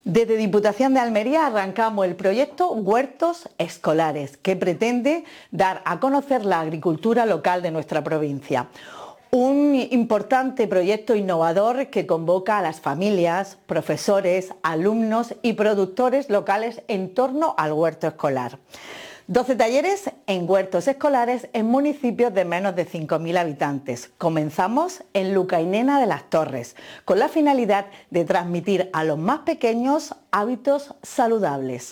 La diputada de Iniciativas Europeas y Emprendimiento, Esther Álvarez destaca que estos talleres, que comienzan en Lucainena de las Torres, impulsan la producción sostenible y agroecológica
23-02_diputada_huertos.mp3.mp3